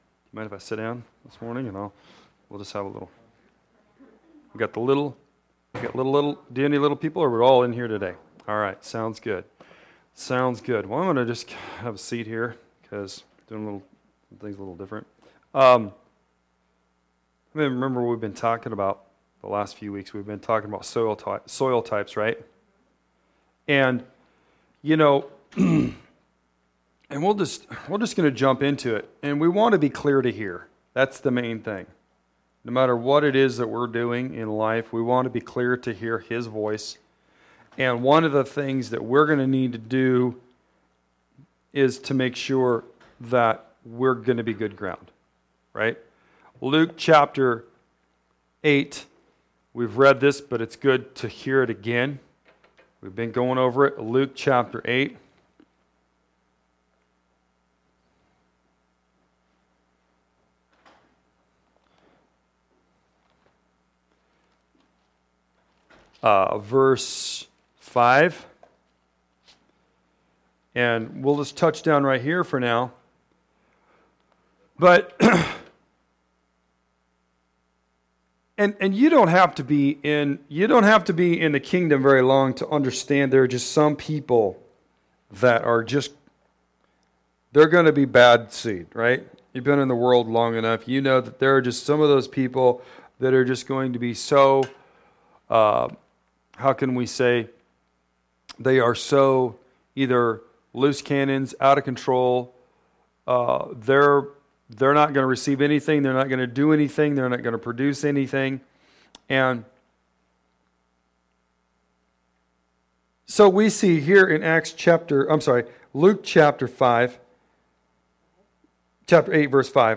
A collection of sermons/pastoral messages from 2018-2022.